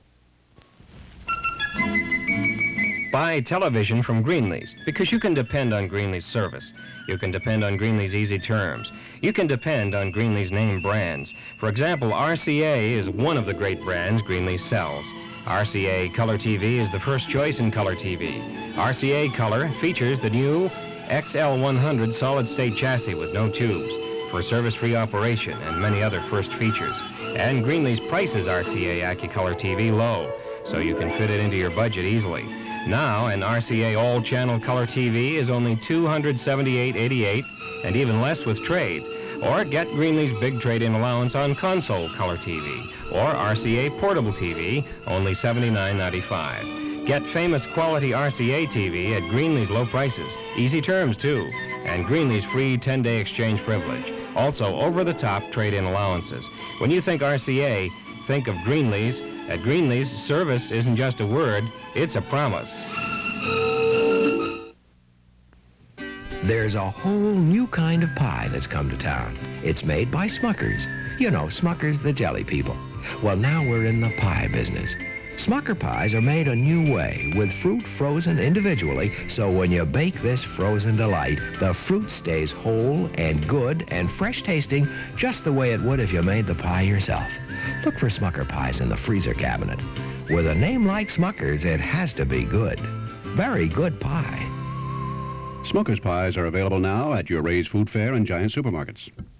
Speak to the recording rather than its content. Here are some neat recordings I was able to save from the early 1970's. These are local radio stations around the thumb area Michigan WGER and WGMZ.